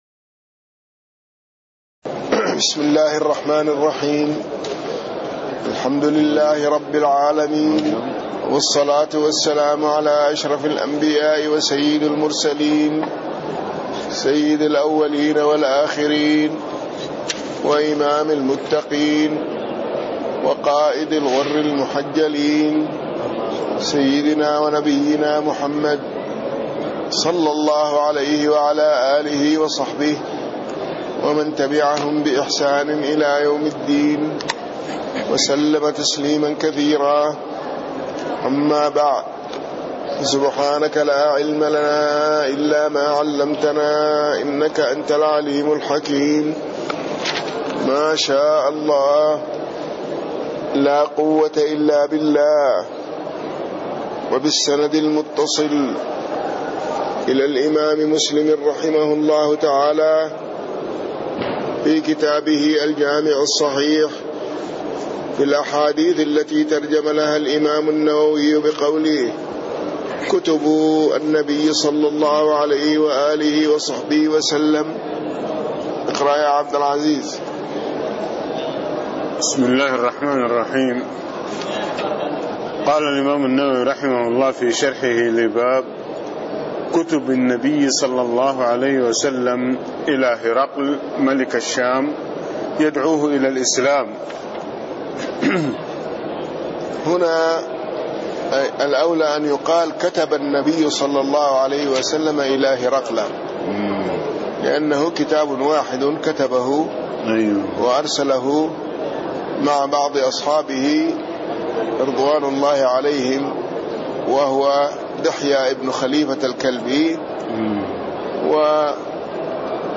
تاريخ النشر ٢٤ شوال ١٤٣٥ هـ المكان: المسجد النبوي الشيخ